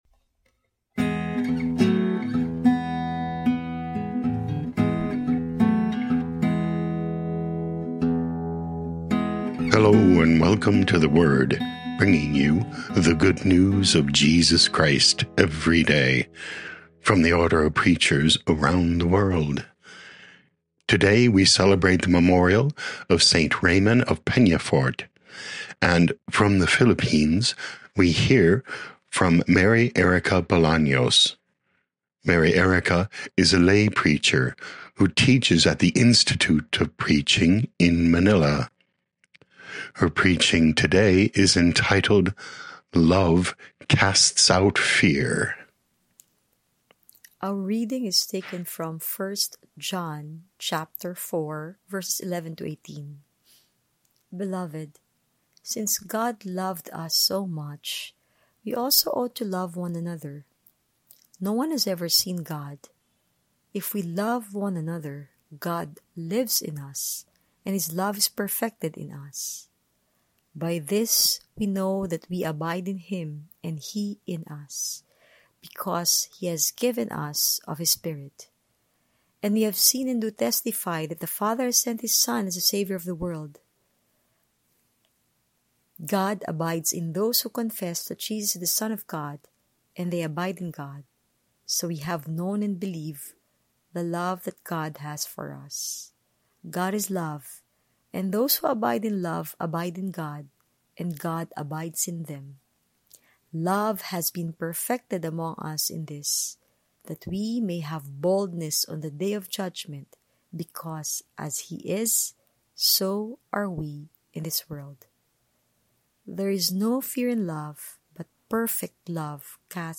daily homilies from the Order of Preachers